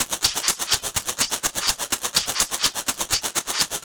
Ruff Shaker.wav